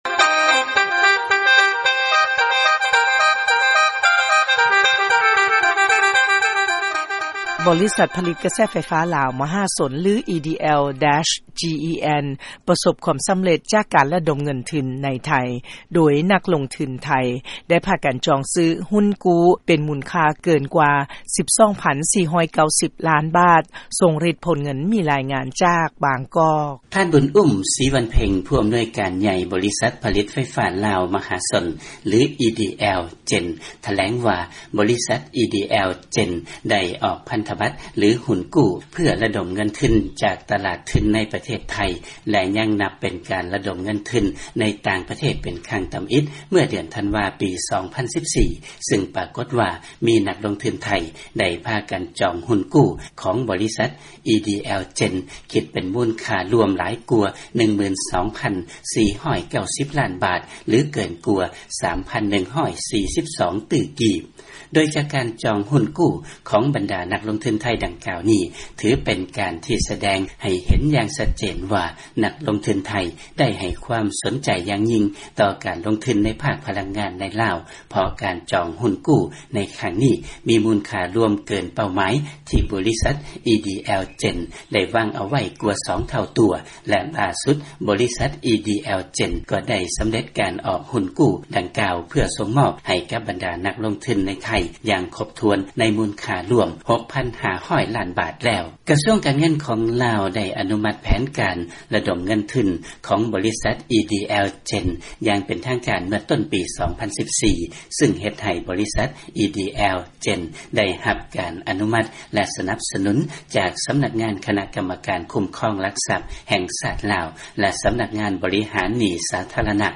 ຟັງລາຍງານ ບໍລິສັດໄຟຟ້າລາວ ປະສົບຄວາມສຳເລັດ ໃນການລະດົມເງິນທຶນ ໃນໄທ.